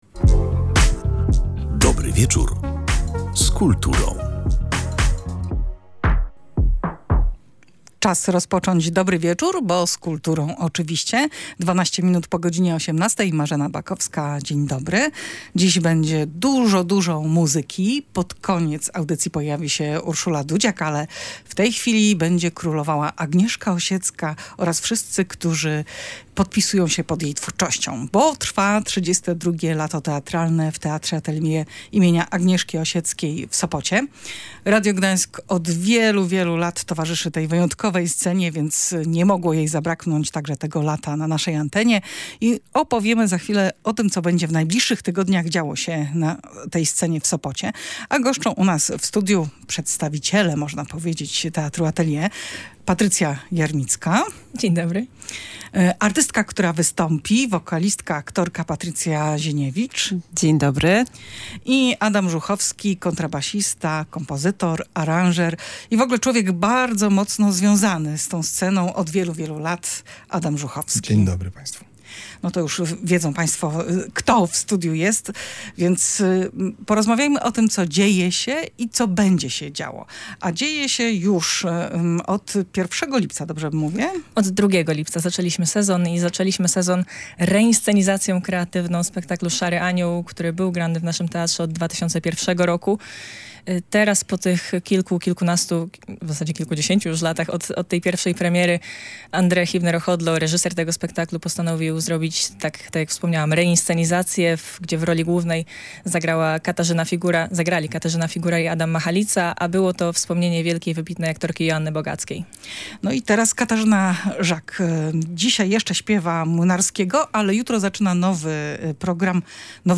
O tym, jakie wydarzenia czekają nas podczas Lata Teatralnego, opowiadali podczas audycji na żywo